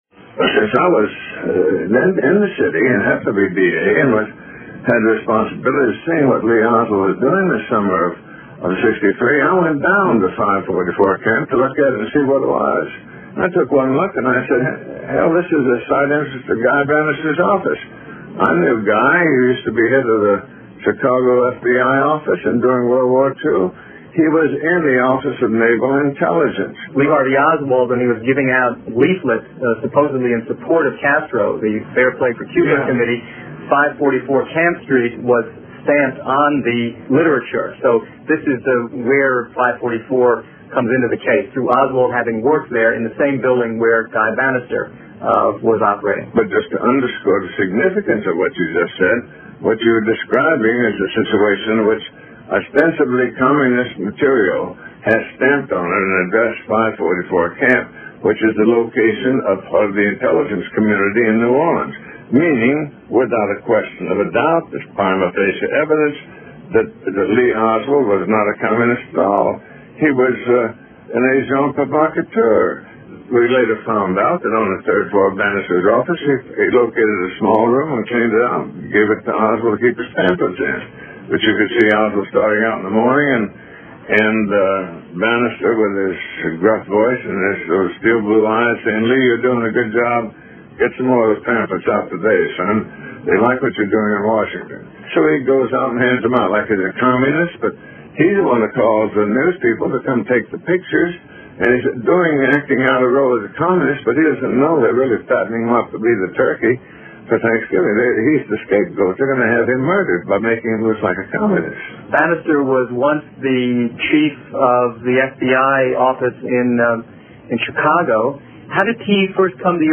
Jim Garisson - JFK 1988 Broadcast 6